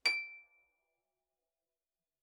KSHarp_D7_f.wav